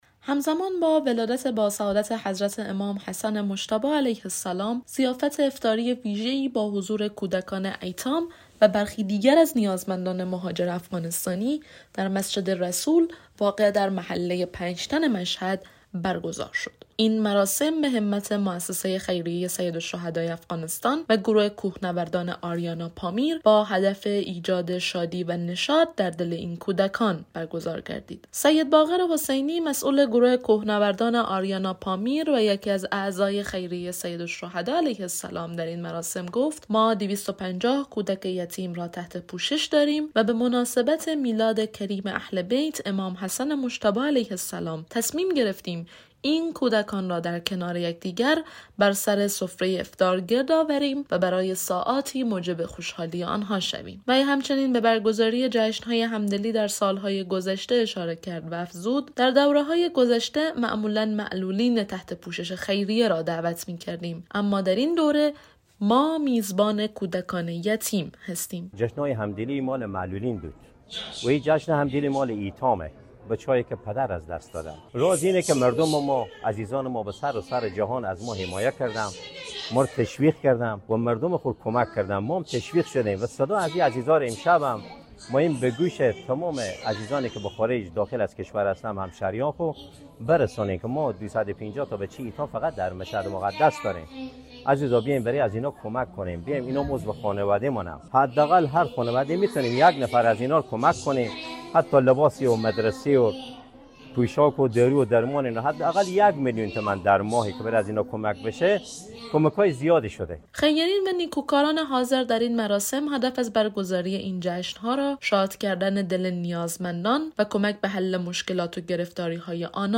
خبرنگار رادیو دری